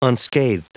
Prononciation du mot unscathed en anglais (fichier audio)
Prononciation du mot : unscathed